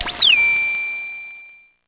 Index of /90_sSampleCDs/300 Drum Machines/Electro-Harmonix Spacedrum
Drum07.wav